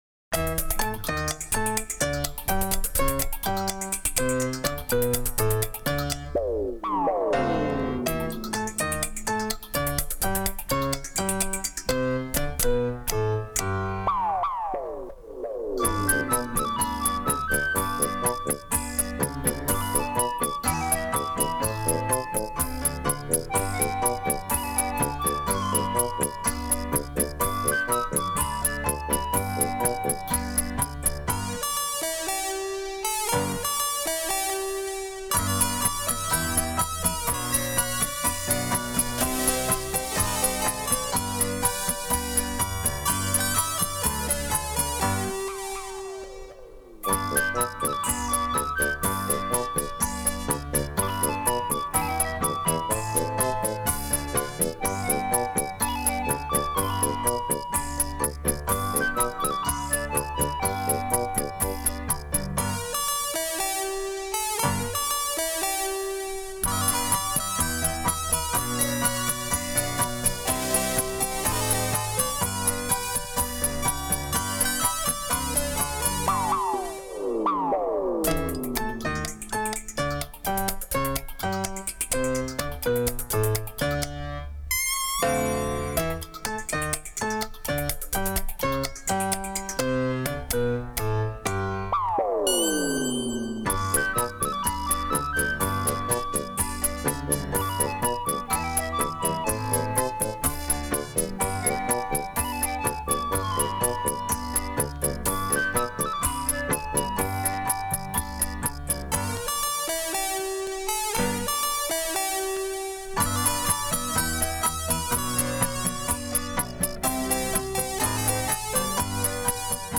黑胶LP
轻松的恰恰（之二）